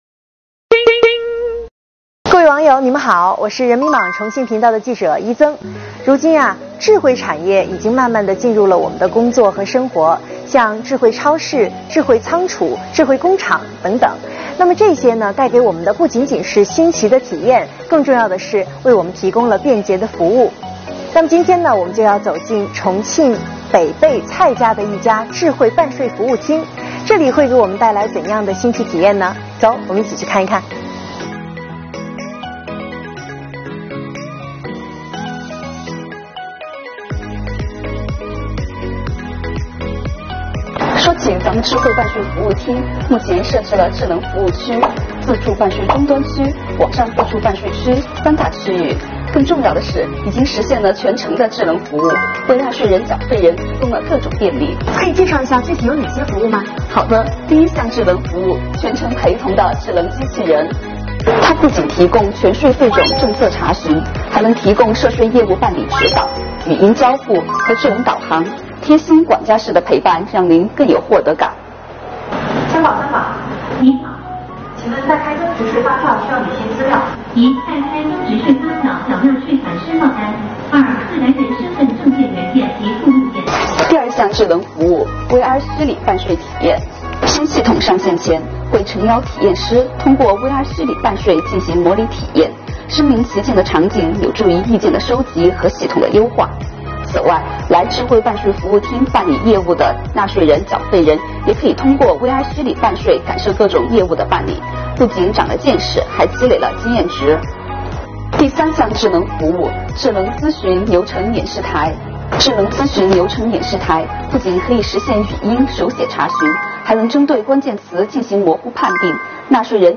今天我们随记者小姐姐走进北碚蔡家的一家
智慧办税服务厅